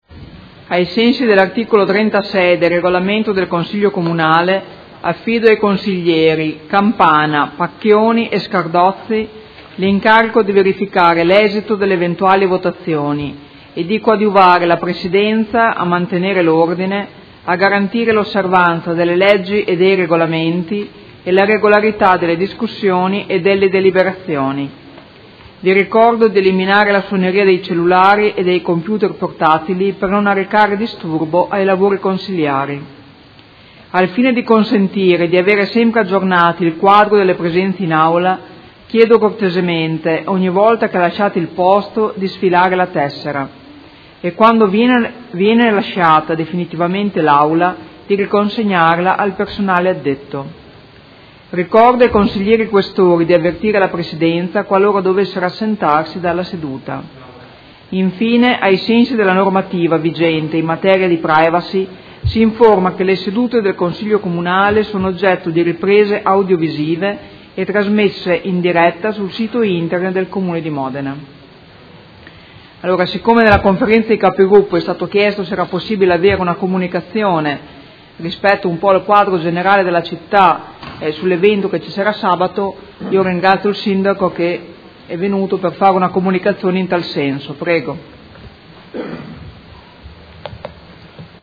Seduta del 26/06/2017. Inizio lavori